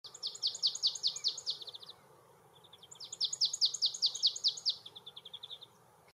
Sumpfmeise Gesang
Sumpfmeise_Gesang.mp3